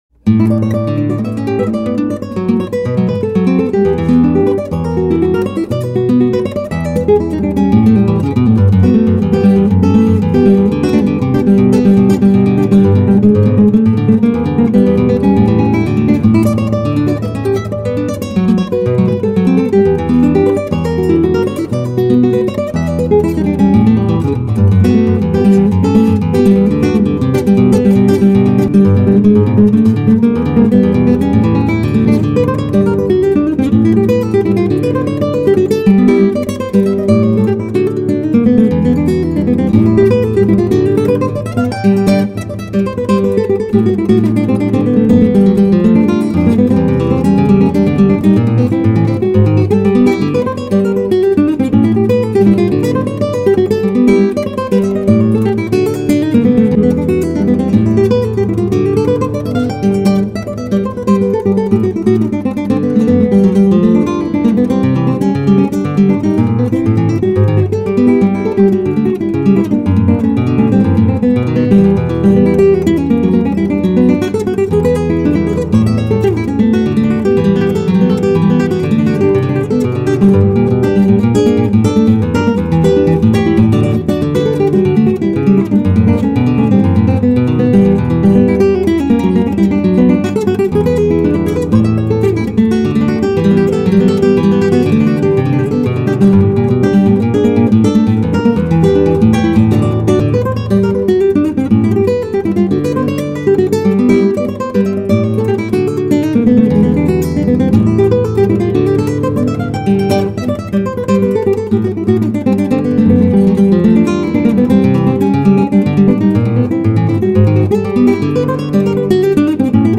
2839   04:13:00   Faixa: 7    Choro(Chorinho)
Violao Acústico 6